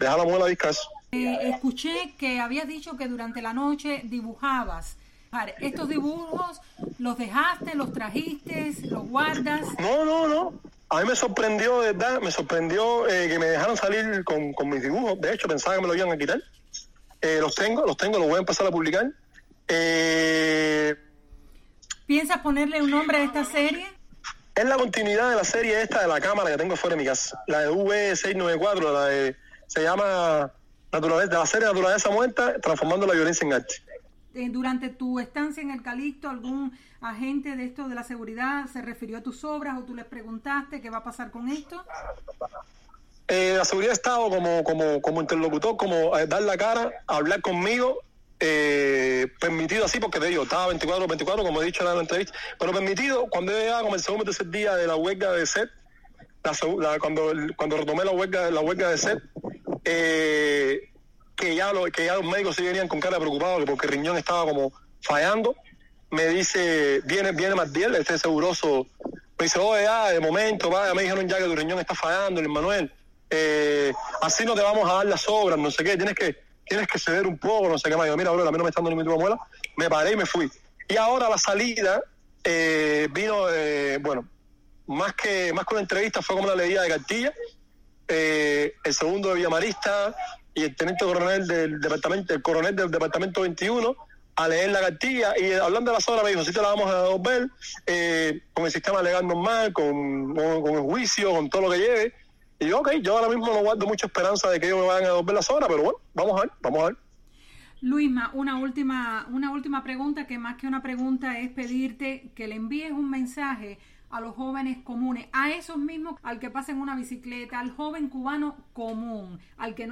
Otero Alcántara responde a las preguntas de Radio Martí (2)